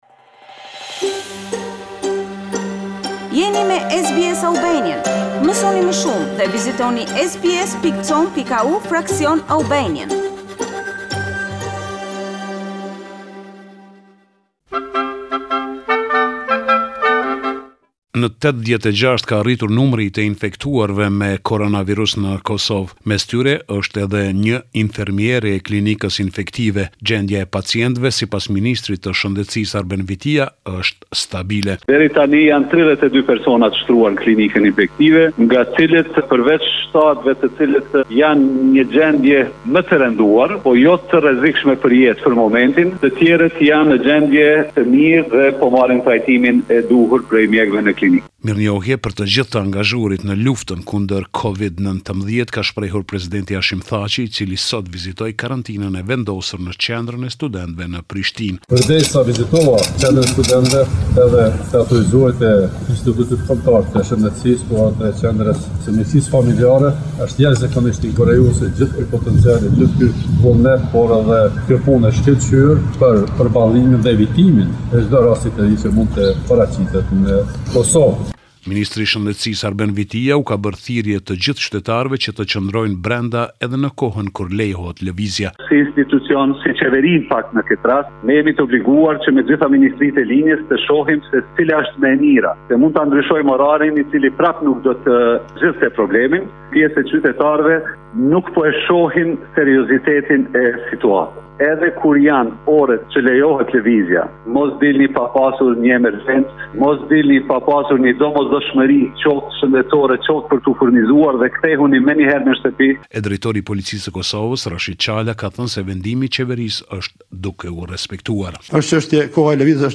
This is a report summarising the latest developments in news and current affairs in Kosova.